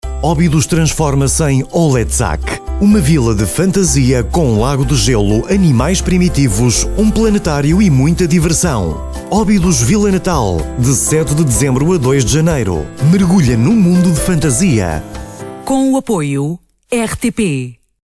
Sprechprobe: Industrie (Muttersprache):
His voice has been described as warm, smooth, sophisticated, natural and youthful.